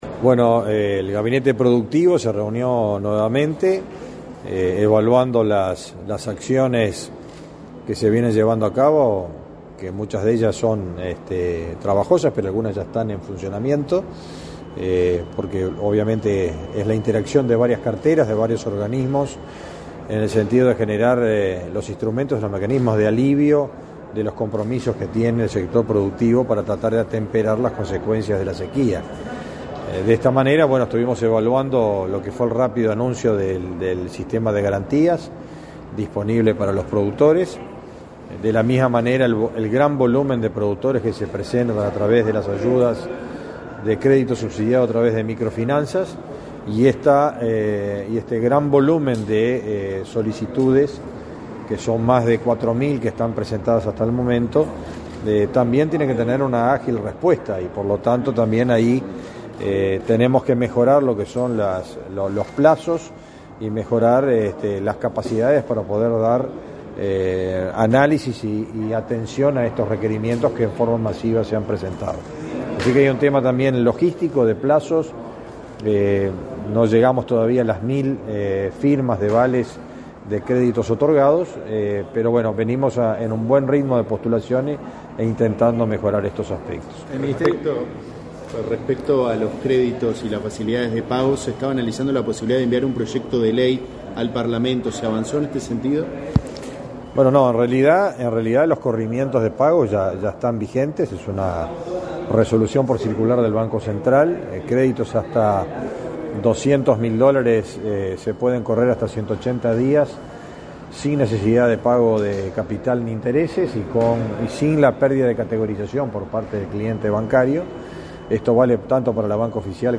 Declaraciones a la prensa del ministro de Ganadería, Fernando Mattos
Declaraciones a la prensa del ministro de Ganadería, Fernando Mattos 15/02/2023 Compartir Facebook X Copiar enlace WhatsApp LinkedIn El Gabinete Productivo se reunió, este 15 de febrero, con el secretario de Presidencia de la República, Álvaro Delgado, para analizar el impacto de la sequía en el país. Tras el encuentro, el ministro de Ganadería, Fernando Mattos, realizó declaraciones a la prensa.